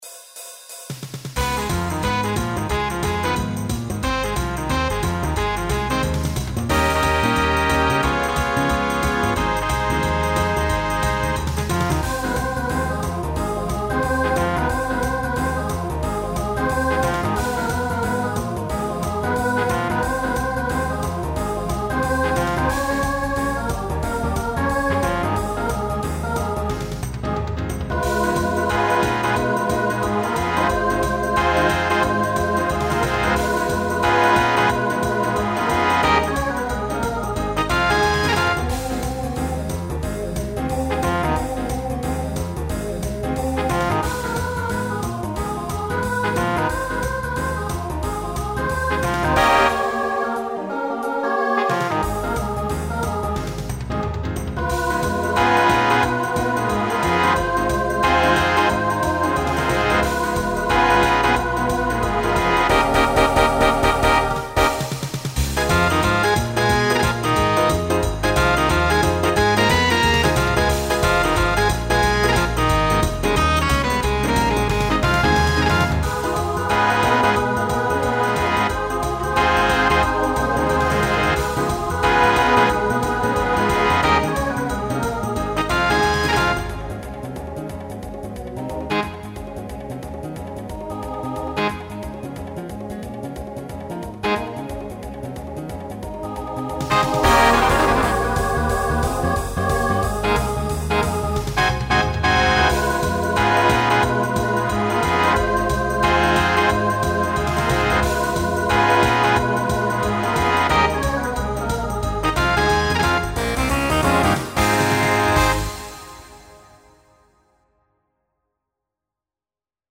Decade 1940s Genre Swing/Jazz Instrumental combo
Voicing SATB